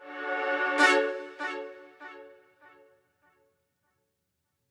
AchievementGetWavePass.mp3